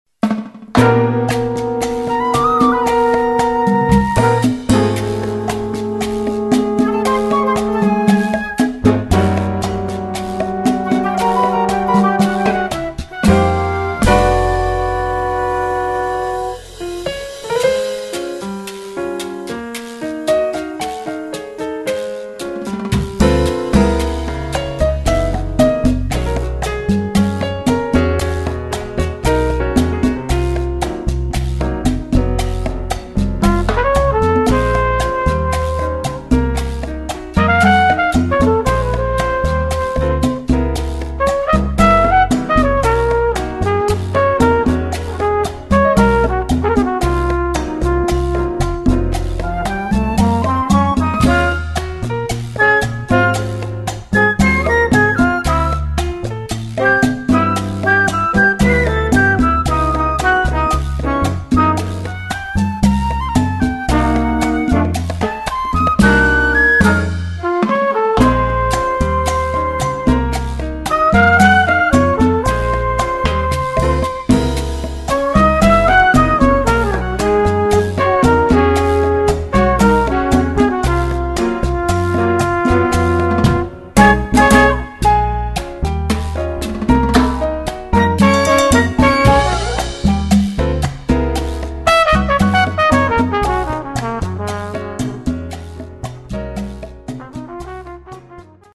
Category: combo (septet)
Style: cha cha